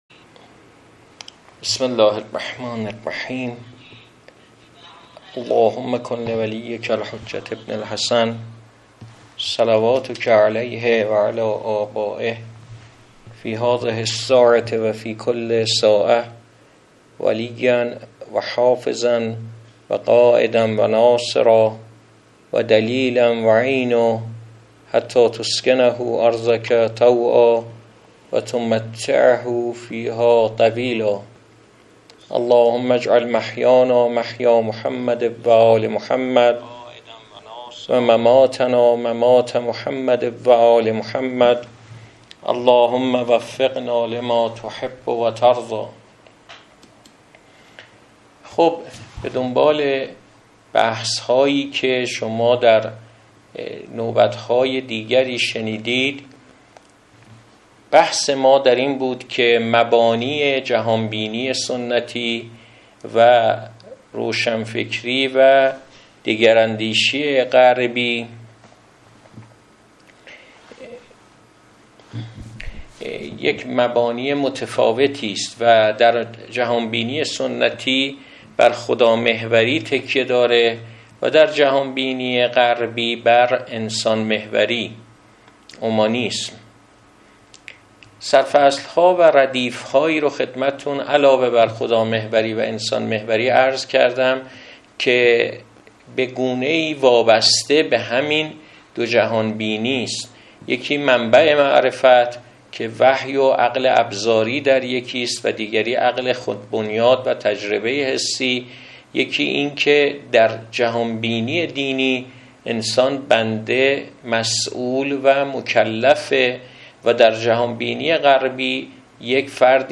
سخنرانی گردهمایی 6 آذرماه 1404